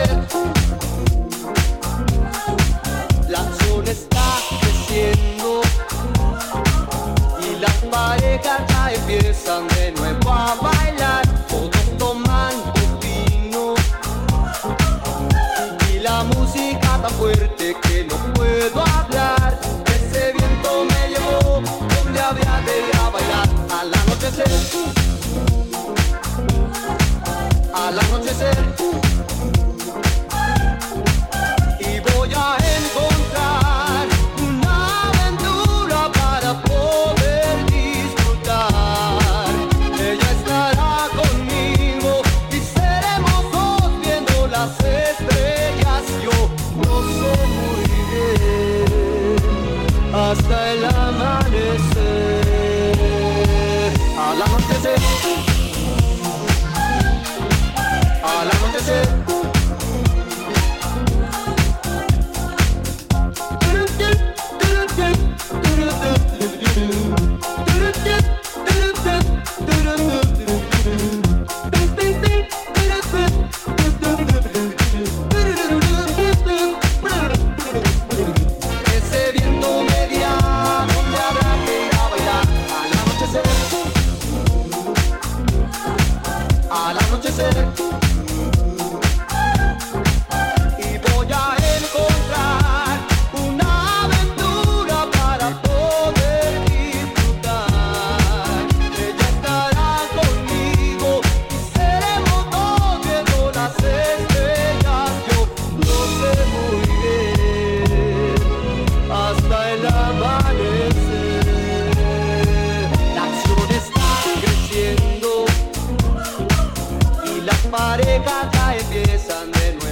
distinctly underground energy
re-energises a disco classic with an upbeat twist